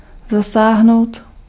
Ääntäminen
IPA: [ɛ̃.tɛʁ.və.niʁ]